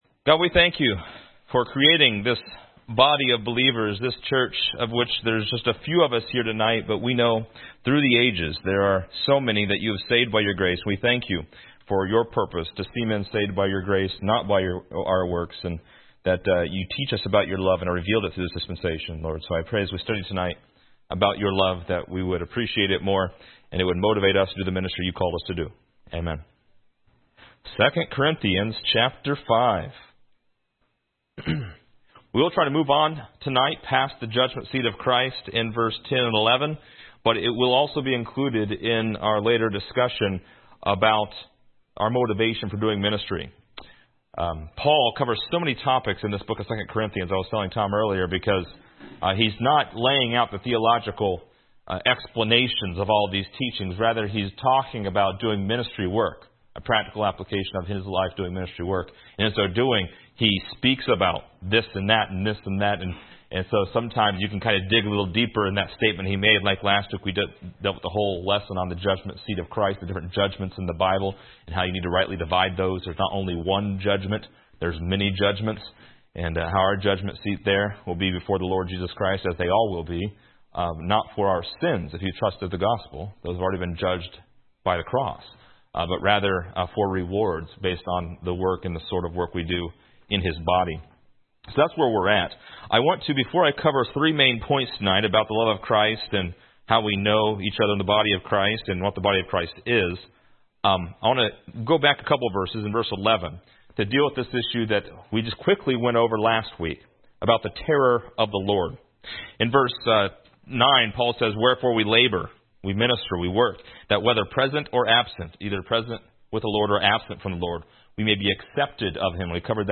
Description: This lesson is part 23 in a verse by verse study through 2 Corinthians titled: A New Creature.